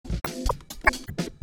JUST_IF, сохраняет мп3 с нажатых клавиш, семплы звуки можеш свои вставить, сейчас работаю над скретчем.